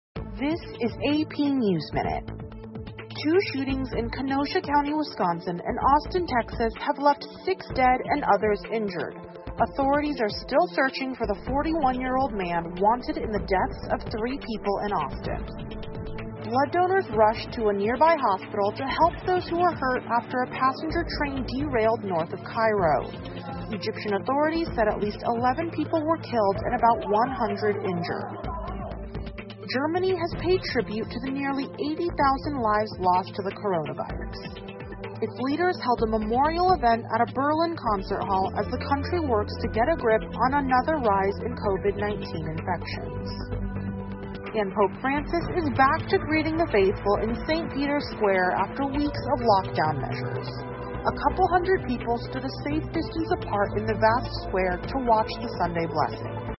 美联社新闻一分钟 AP 埃及一客运列车脱轨造成11人死亡 听力文件下载—在线英语听力室